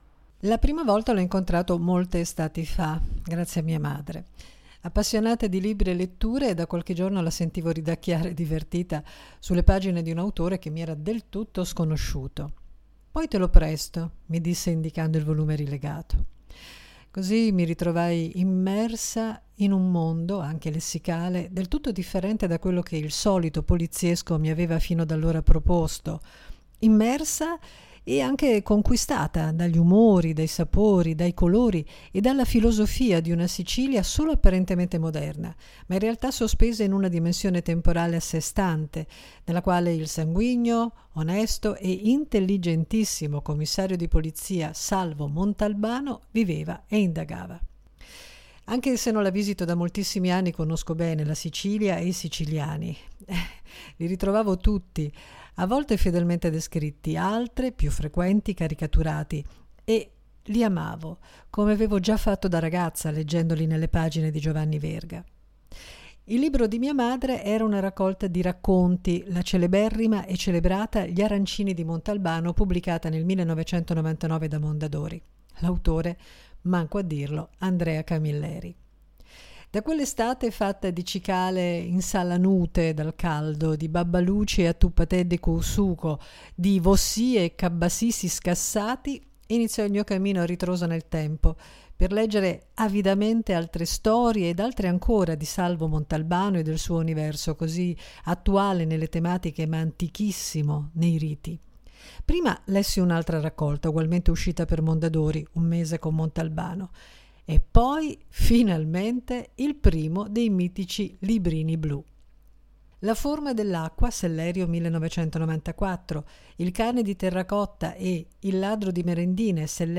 In alto, in questa pagina, l’audio dell’articolo